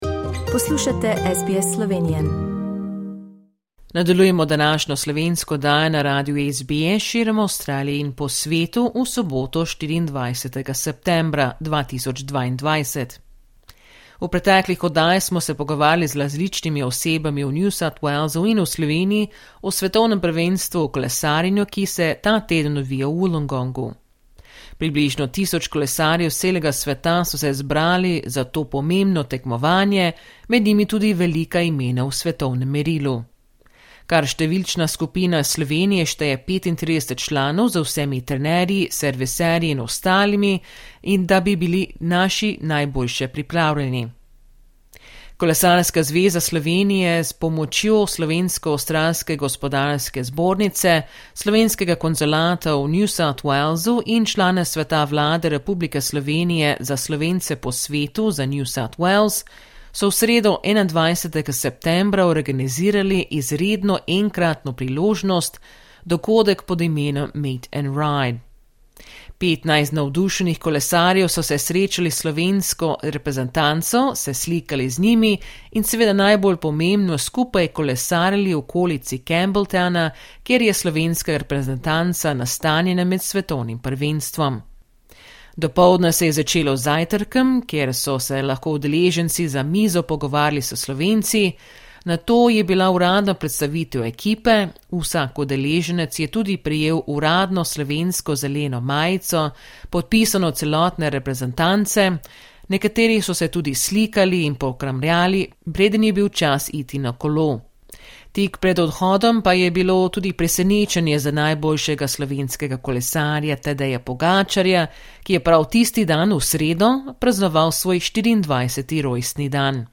Credit: SBS Slovenian Credit: SBS Slovenian V četrtek, 22.septembra, pa je slovenska reprezentanca imela uradno tiskovno konferenco.